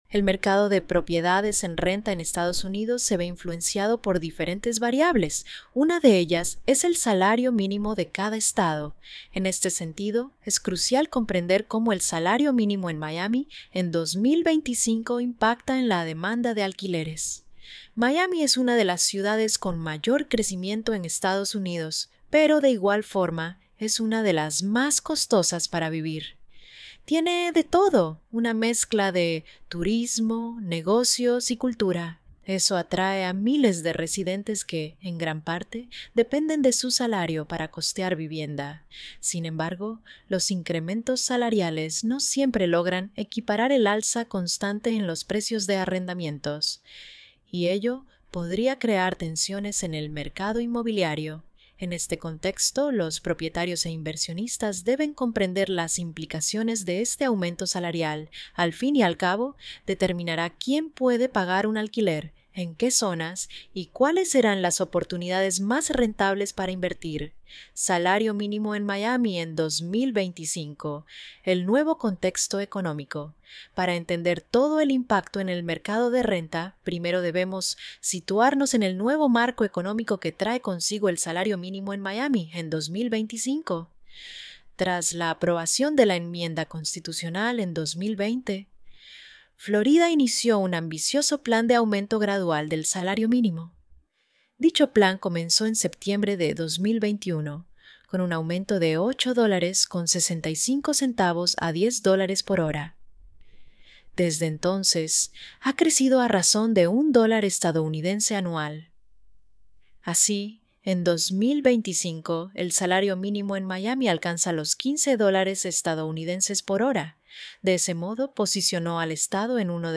▶ Escucha el artículo aquí: Salario mínimo en Miami